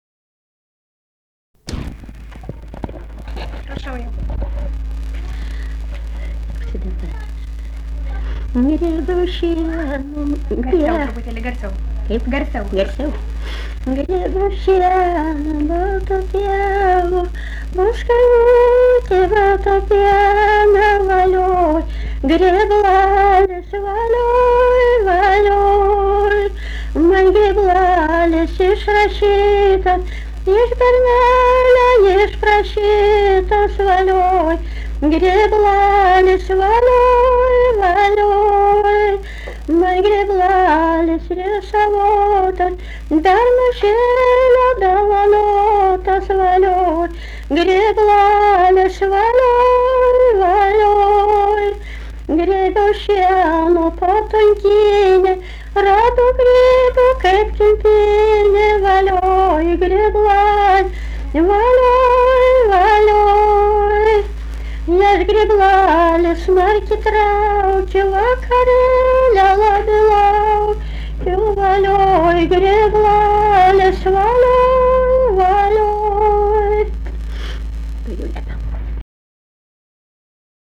daina, kalendorinių apeigų ir darbo
Skaistgiriai
vokalinis